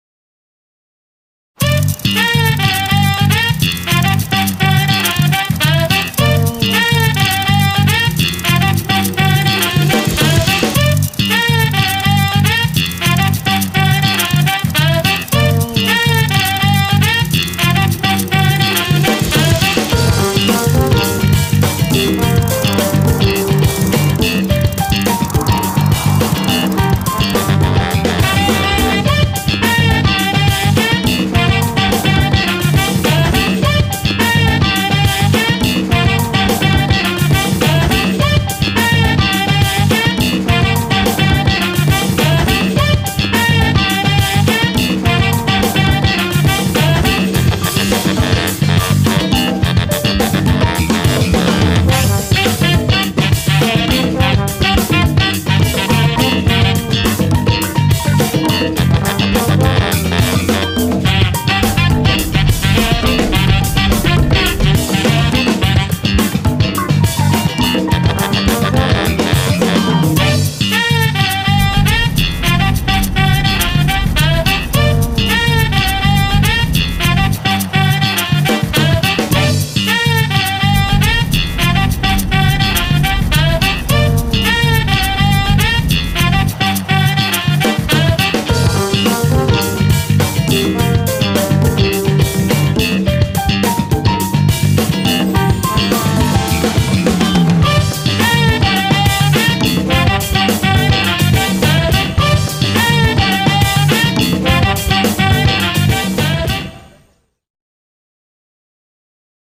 tema dizi müziği, neşeli eğlenceli enerjik fon müziği.